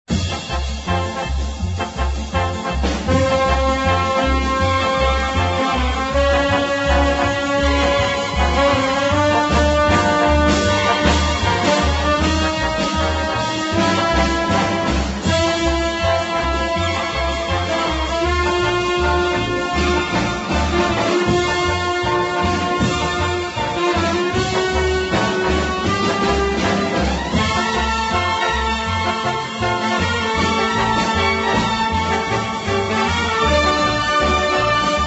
1962 funny fast instr.